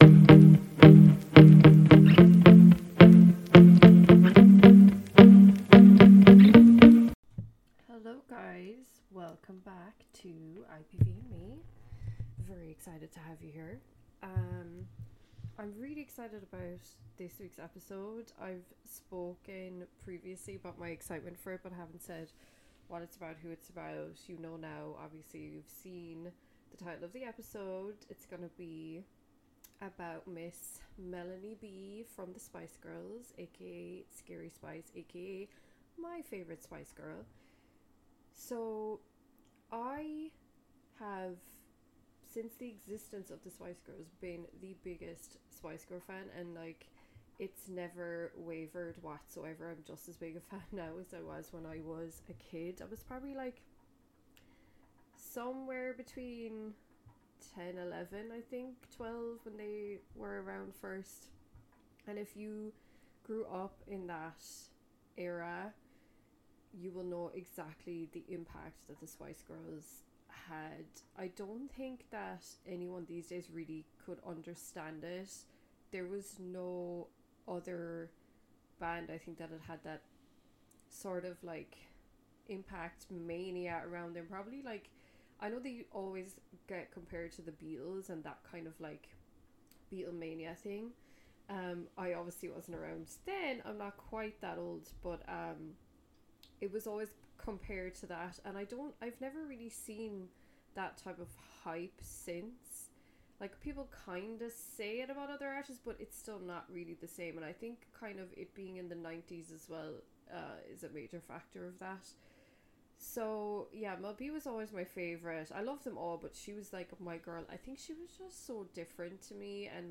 (Apologies for the sound on this one guys! It's a little low. )